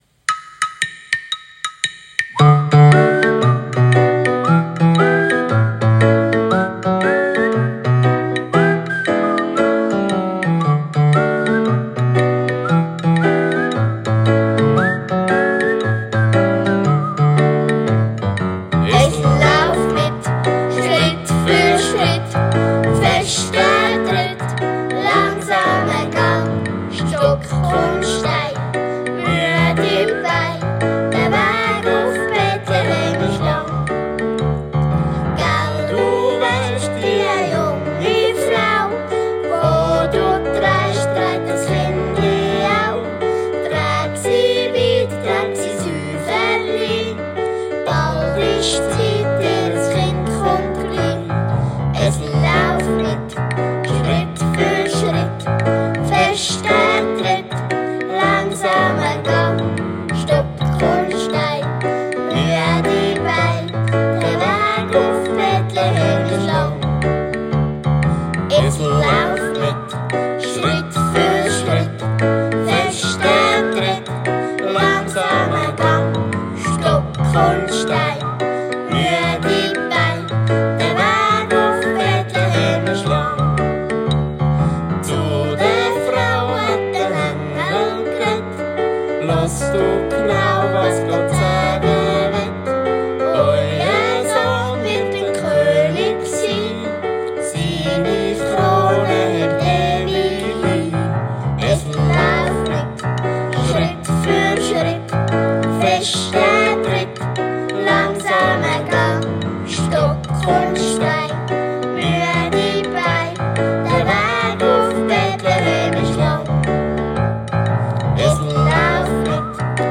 Lieder zum Üben für den Kinderchor